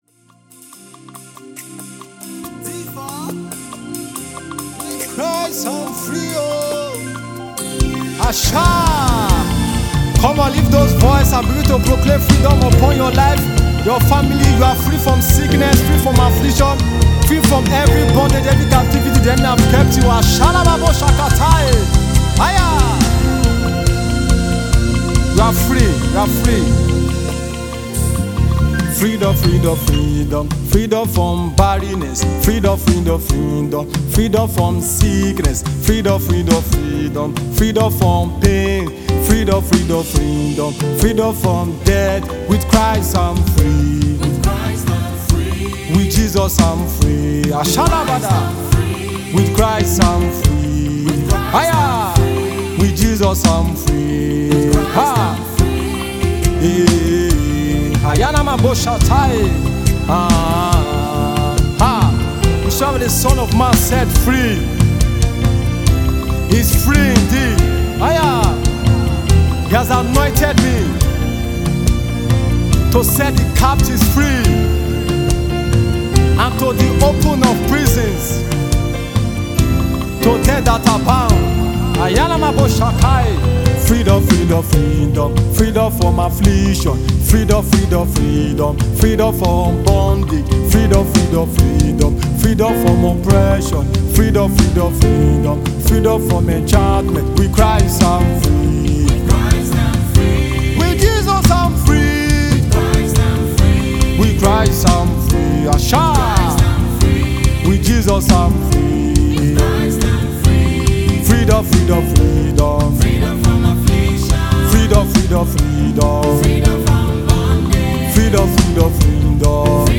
musicWorship